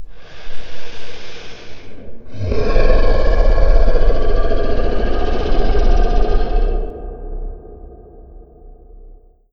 cthulhu-growl.wav